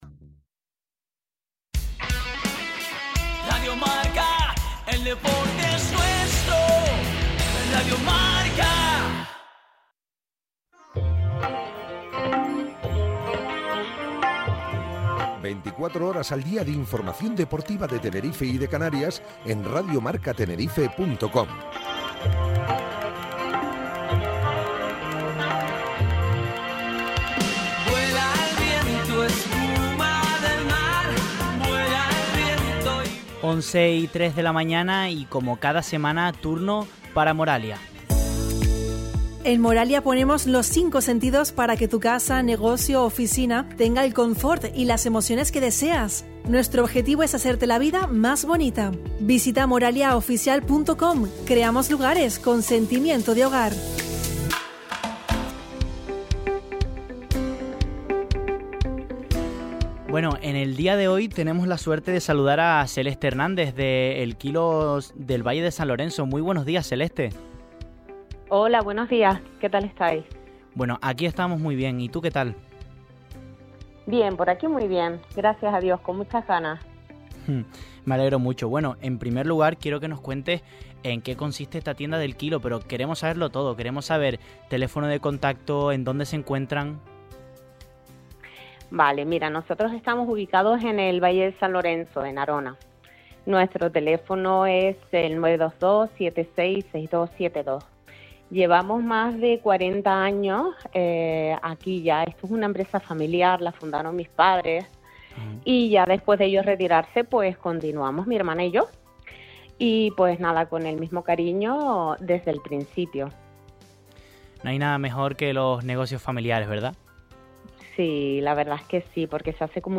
Entrevista-Radio-Marca-en-colaboracion-con-Moralia-El-Kilo-VSL.mp3